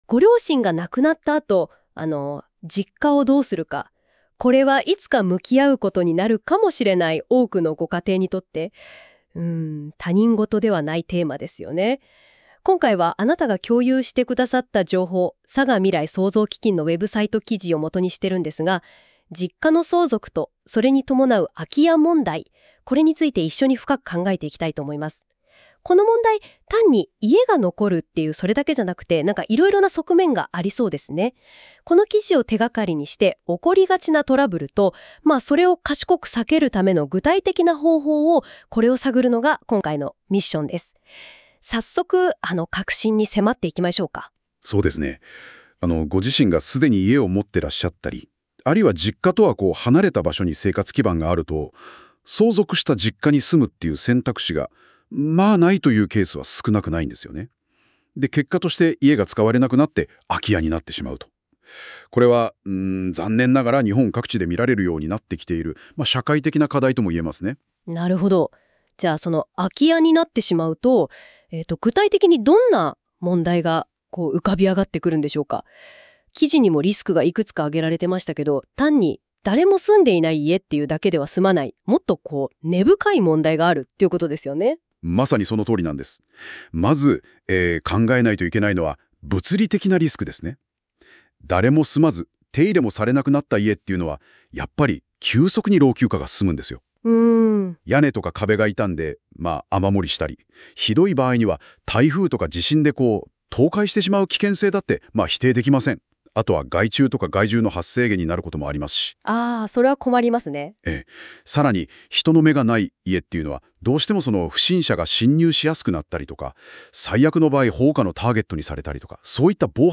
このページの内容をAI要約音声で聞きたい場合は、下の再生ボタンをクリック